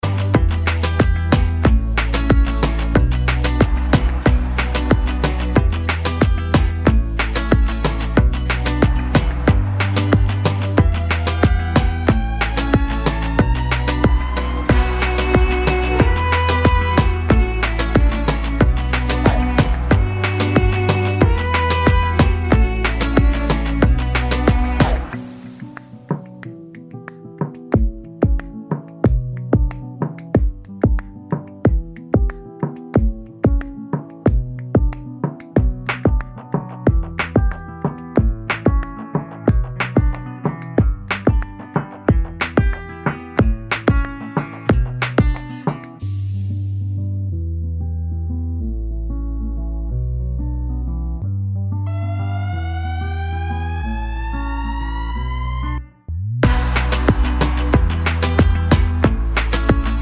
o Backing Vocals) Finnish 3:14 Buy £1.50